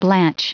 Prononciation du mot blanch en anglais (fichier audio)
Prononciation du mot : blanch